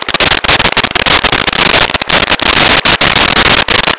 Sfx Amb Brook Loop
sfx_amb_brook_loop.wav